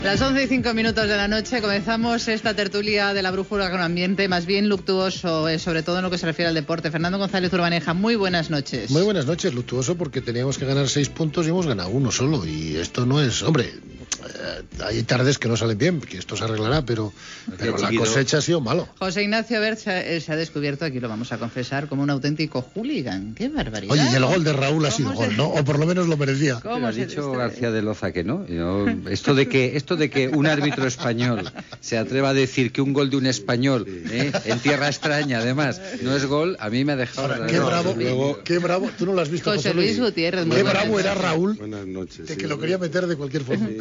Careta i inici del programa, cimera hispano francesa a Màlaga.
Hora, presentació dels integrants de la tertúlia amb comentaris de futbol
Informatiu